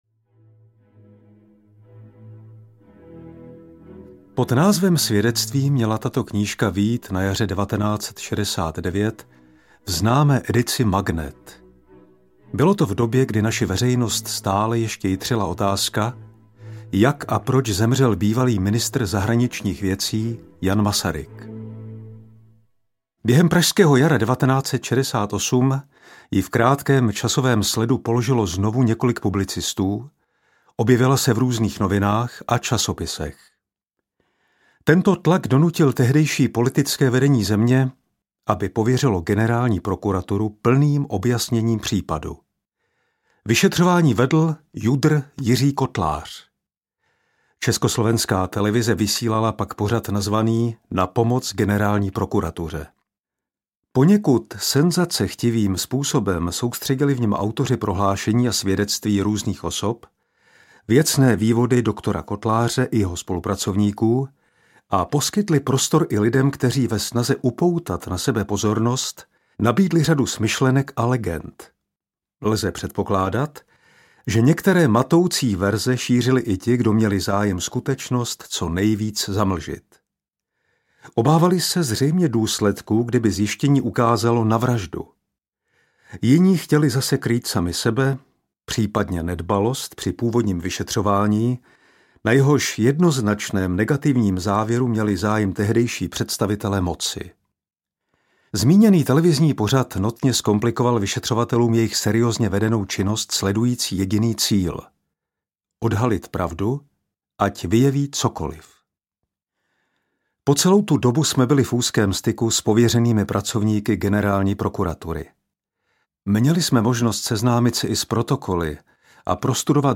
Proč zemřel Jan Masaryk? audiokniha
Ukázka z knihy
• InterpretFrantišek Kreuzmann